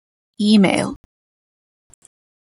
📧 email a 🐌 Senso Concetti sinonimi Traduzioni Notes Extra tools (Inglese) (masculine) email (masculine) email address Frequenza C2 Pronunciato come (IPA) /ˈimeil/ Etimologia (Inglese) Unadapted borrowing from English.